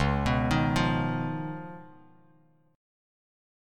C#9 Chord
Listen to C#9 strummed